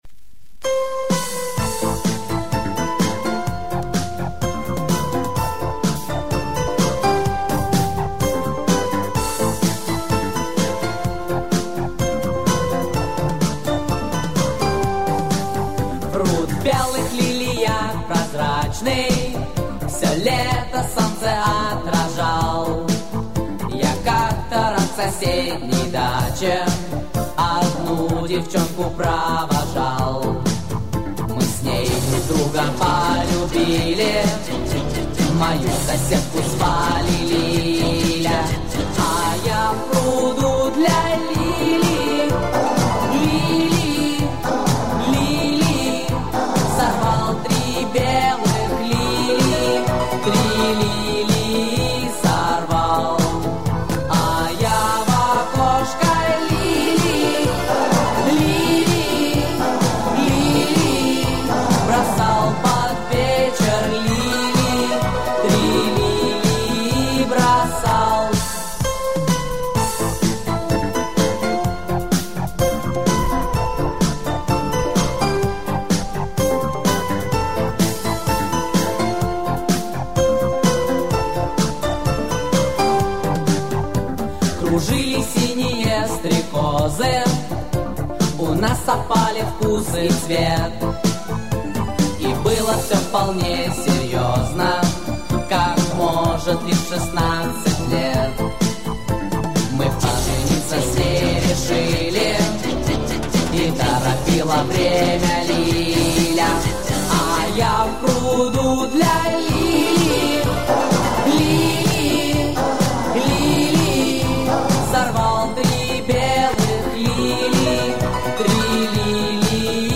Вот запись с пластинки